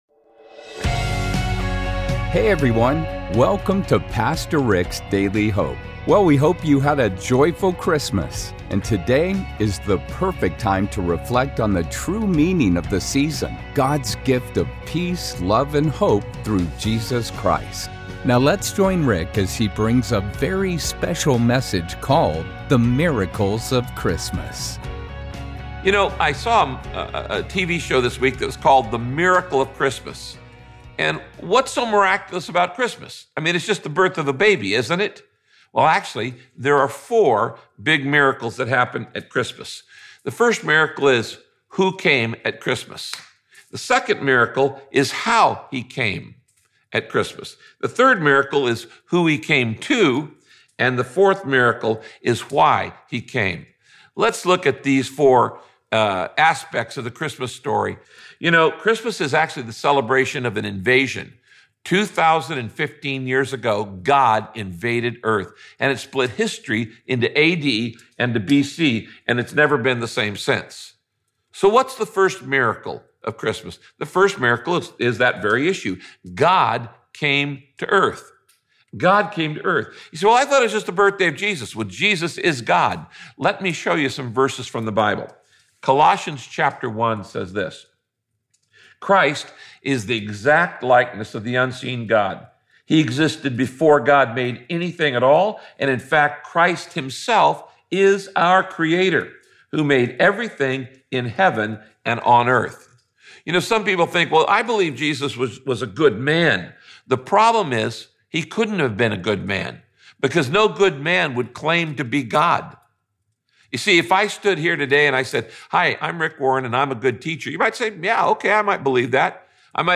In this message by Pastor Ric…